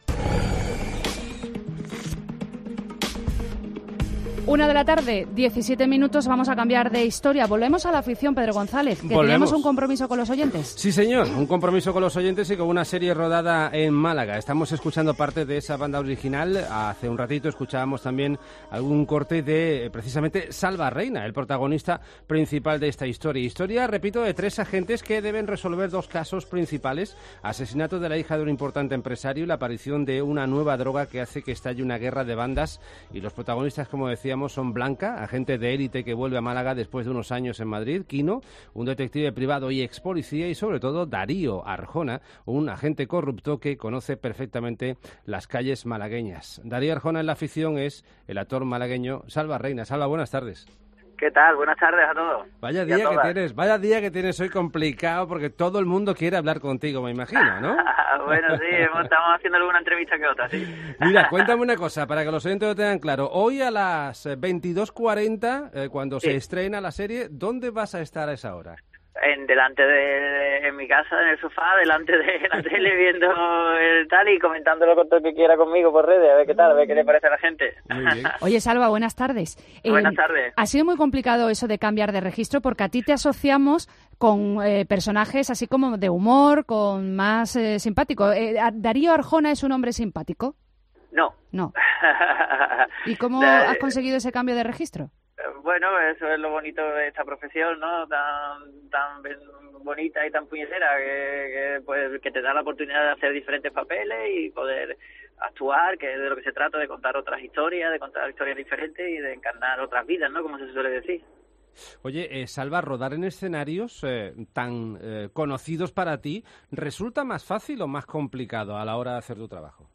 ‘Se tira de ficción, pero son cosas que pueden ocurrir no solo en Málaga sino en cualquier lugar de España’ cuenta Salva Reina en Herrera en COPE MÁS Málaga.